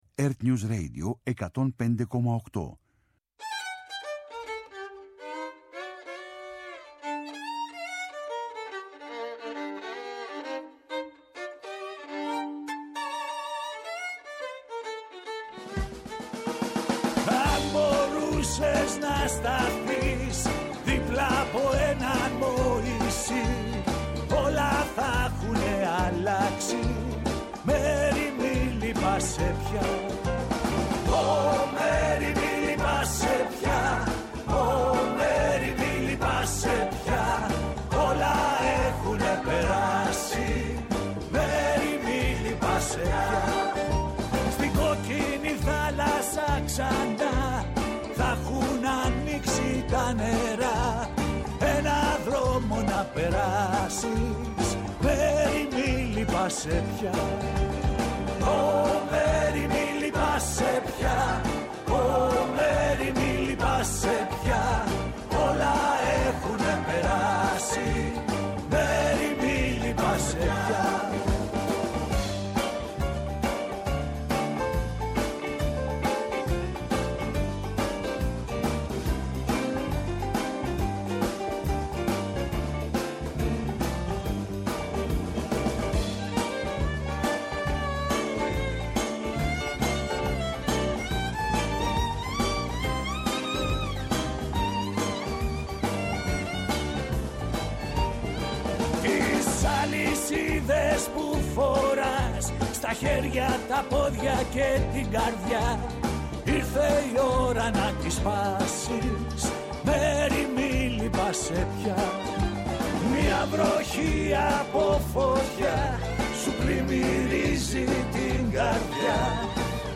-Σύνδεση με Βουλή – ομιλία : Πέτη Πέρκα